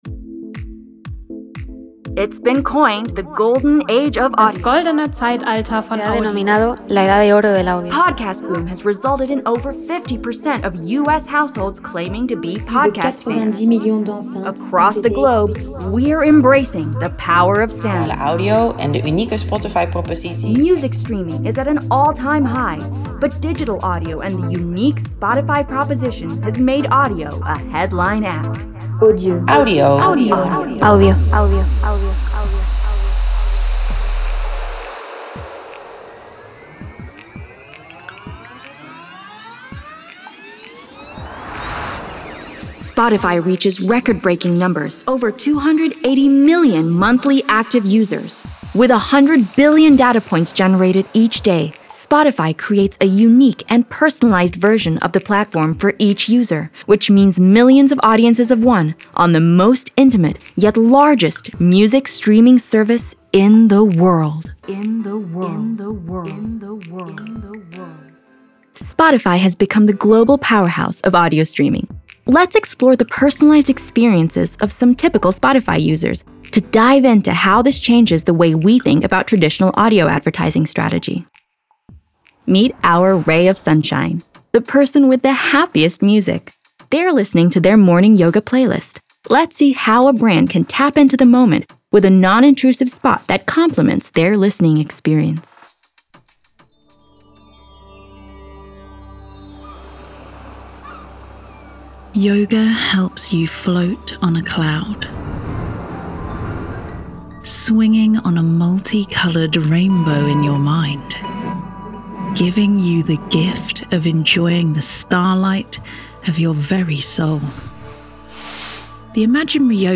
RAC_Love_Audio_Spotify_UK_Binaural_Print_V4_S5OC_300620.mp3